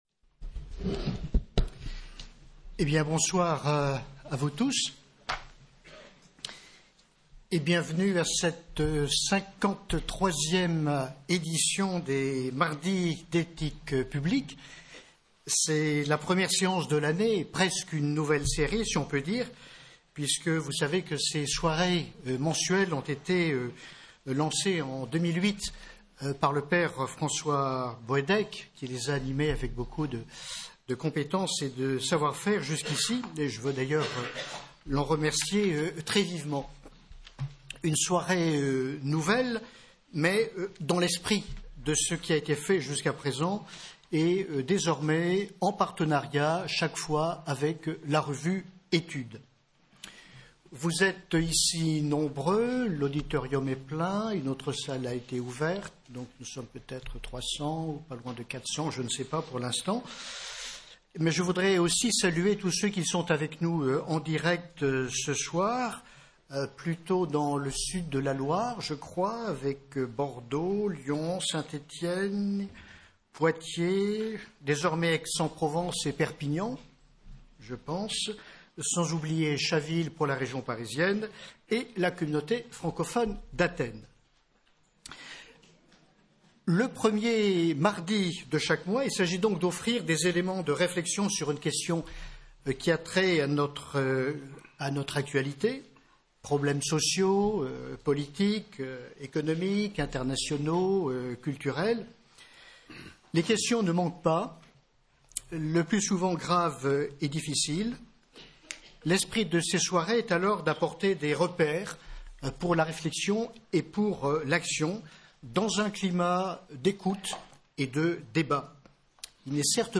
Avec la participation de : – M. Jean-Pierre Winter, psychanalyste, écrivain
– Sr Véronique Margron, théologienne moraliste